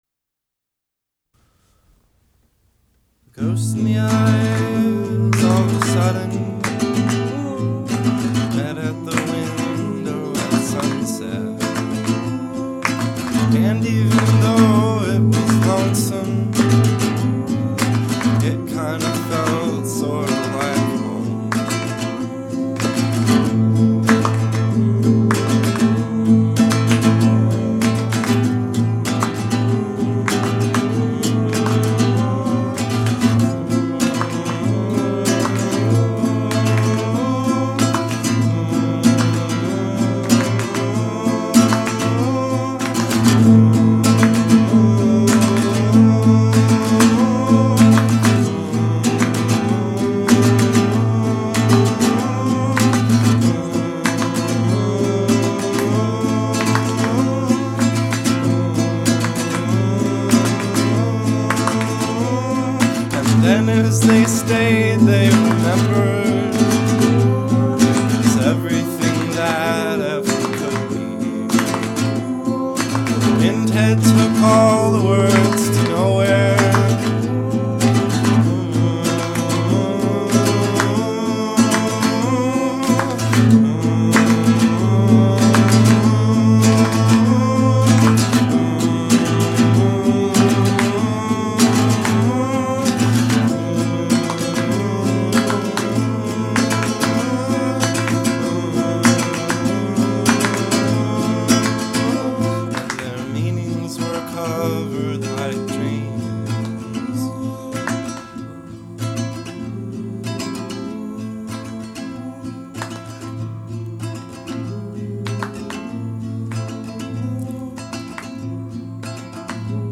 Remastered and sounds golden.